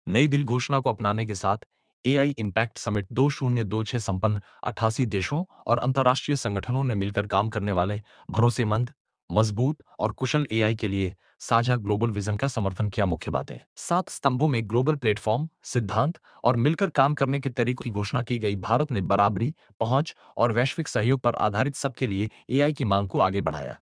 ratan_tts_audio.mp3